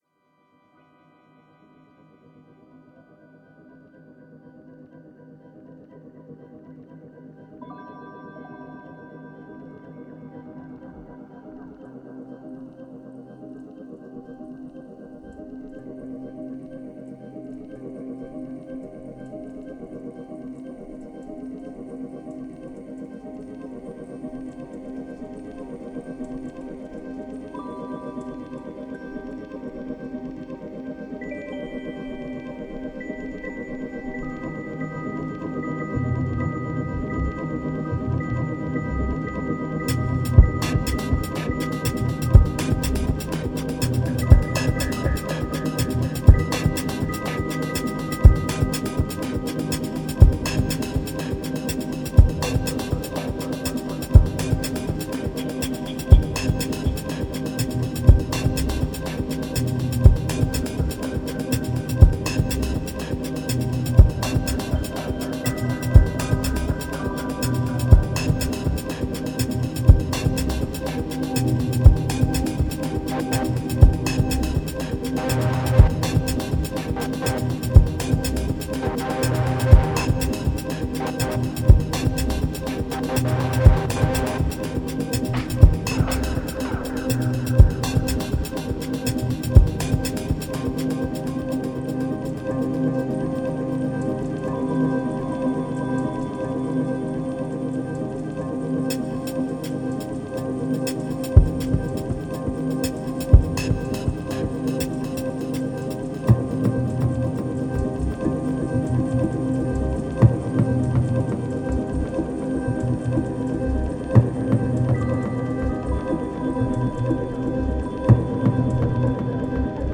2374📈 - 87%🤔 - 122BPM🔊 - 2011-11-16📅 - 533🌟